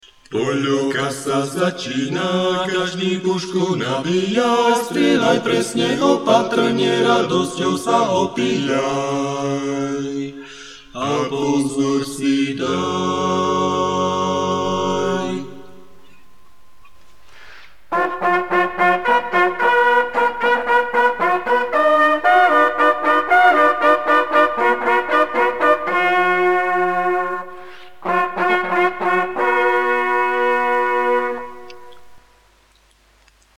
Poľovnícke signály